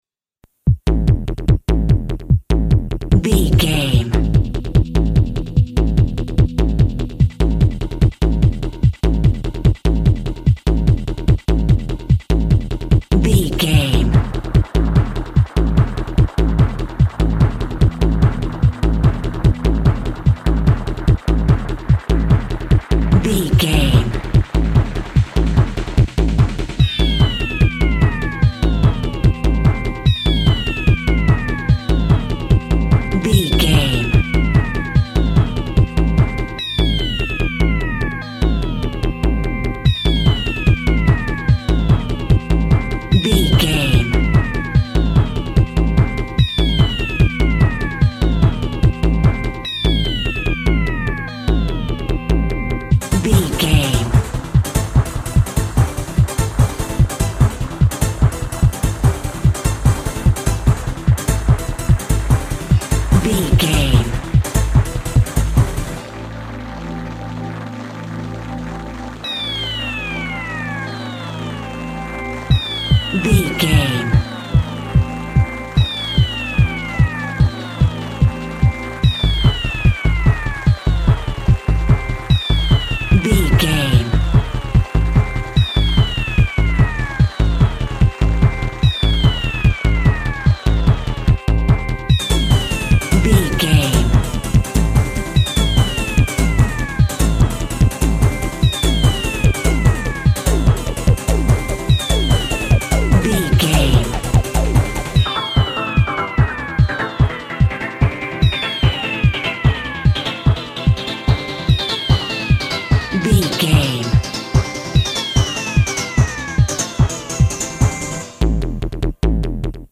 Aeolian/Minor
energetic
futuristic
hypnotic
techno
synth lead
synth bass
Electronic drums
Synth pads